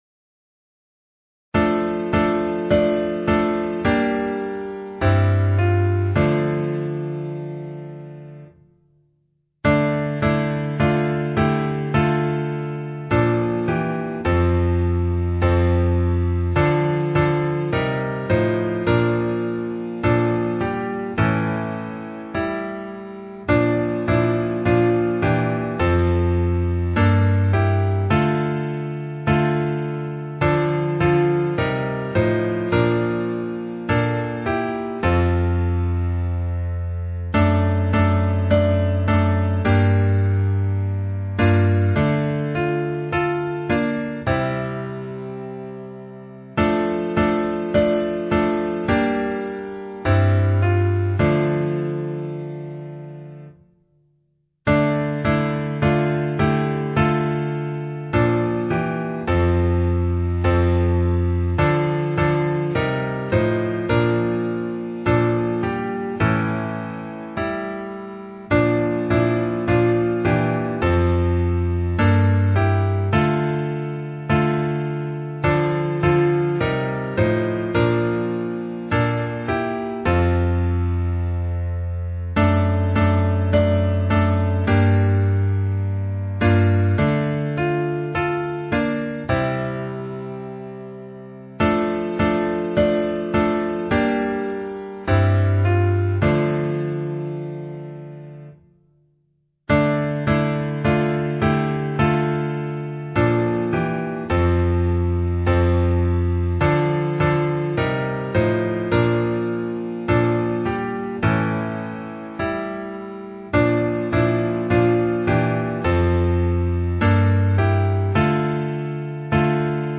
Key: Dm